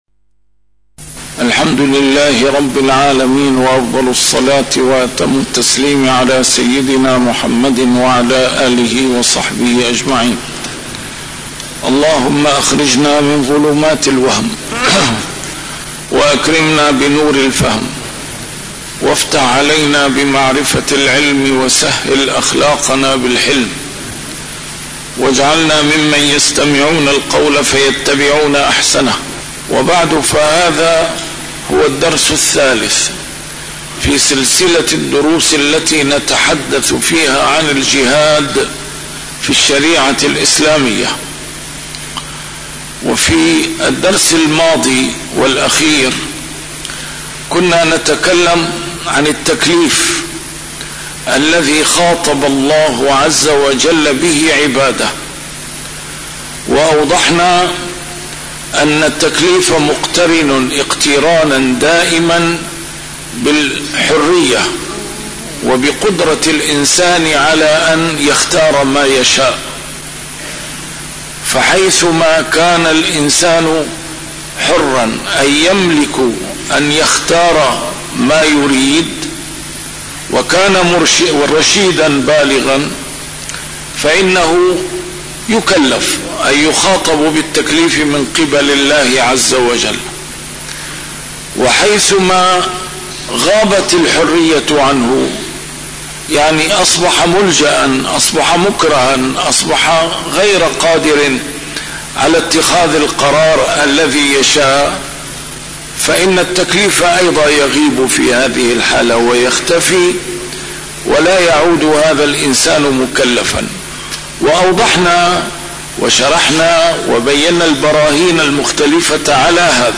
A MARTYR SCHOLAR: IMAM MUHAMMAD SAEED RAMADAN AL-BOUTI - الدروس العلمية - الجهاد في الإسلام - تسجيل قديم - الدرس الثالث: مسؤولية الدعوة - ضوابطها وأهدافها